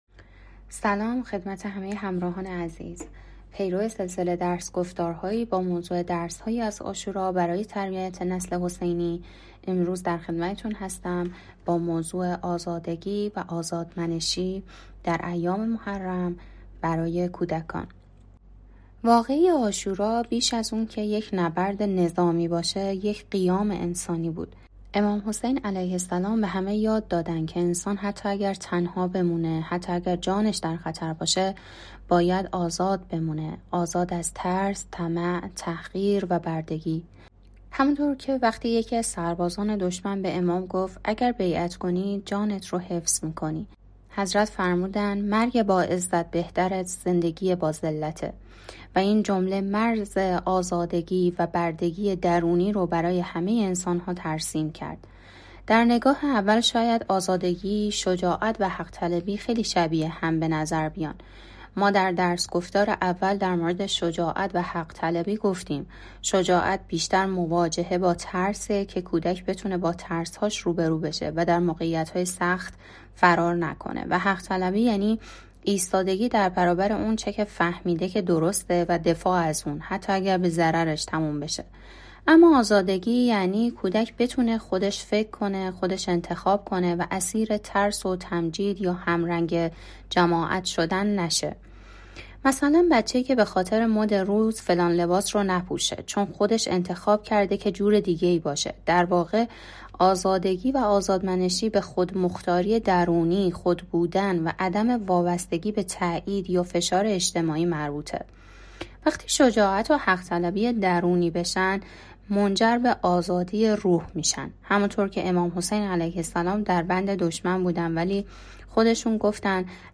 درس‌گفتار‌هایی